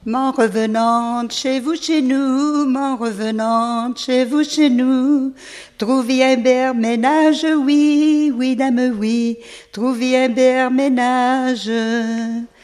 Mémoires et Patrimoines vivants - RaddO est une base de données d'archives iconographiques et sonores.
danse : ronde : grand'danse
Genre laisse
Pièce musicale inédite